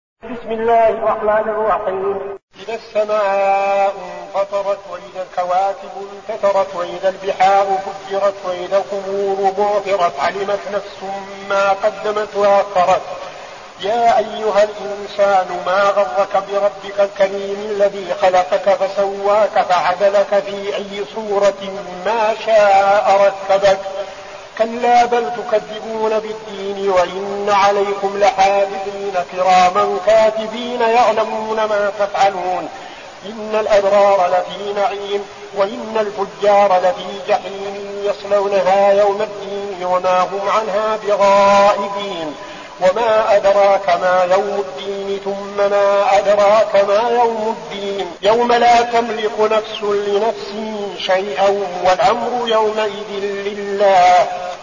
المكان: المسجد النبوي الشيخ: فضيلة الشيخ عبدالعزيز بن صالح فضيلة الشيخ عبدالعزيز بن صالح الانفطار